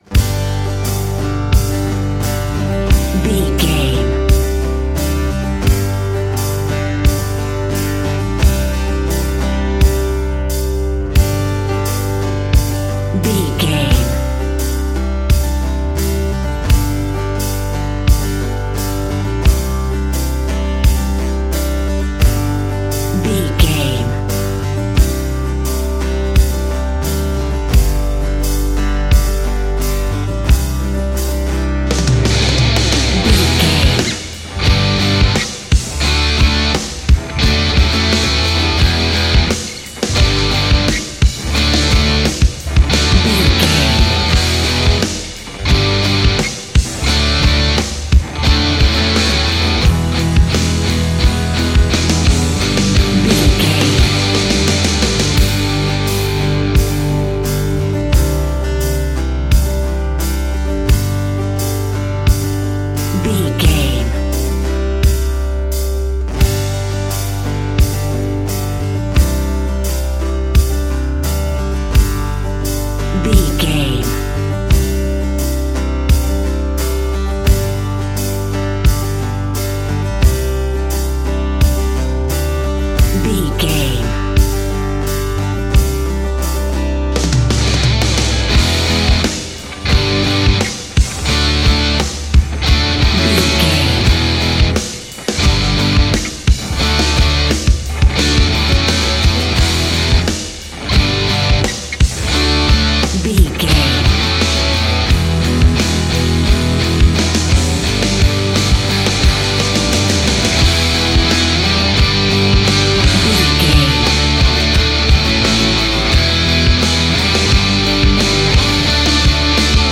Ionian/Major
electric guitar
drums
acoustic guitar
bass guitar